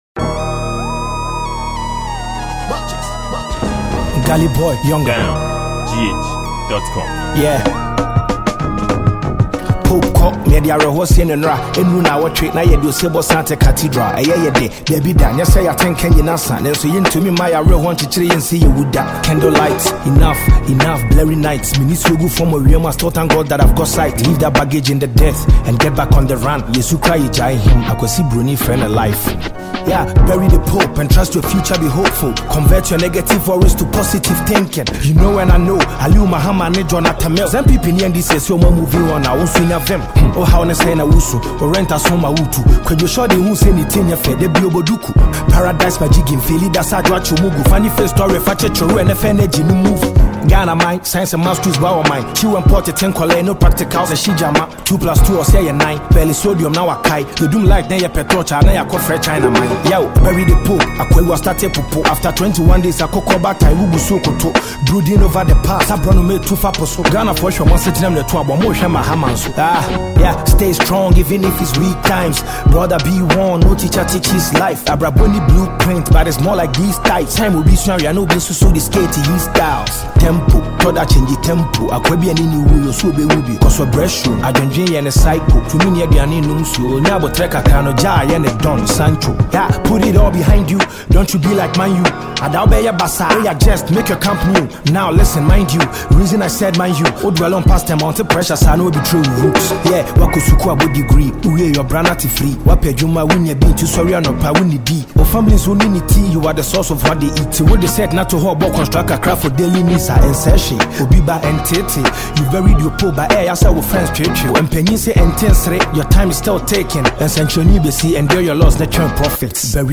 Ghana Music
Ghanaian song